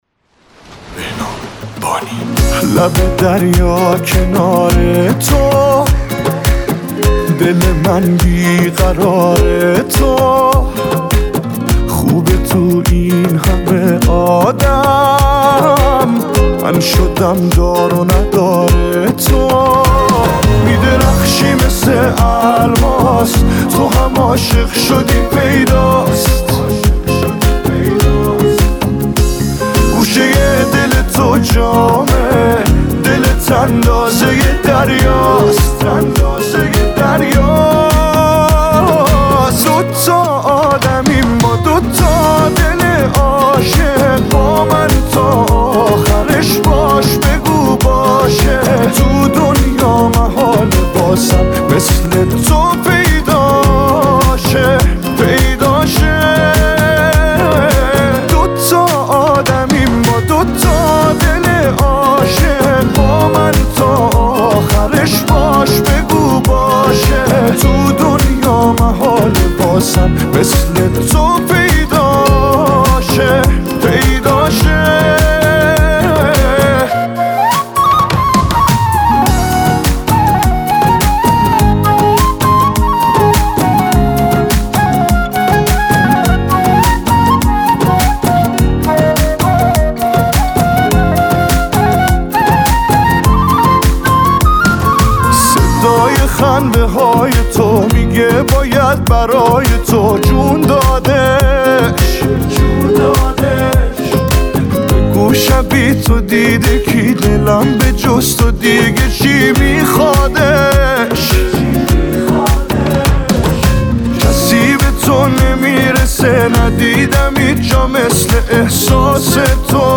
Sing: Pop - آواز: پاپ